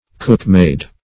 Meaning of cookmaid. cookmaid synonyms, pronunciation, spelling and more from Free Dictionary.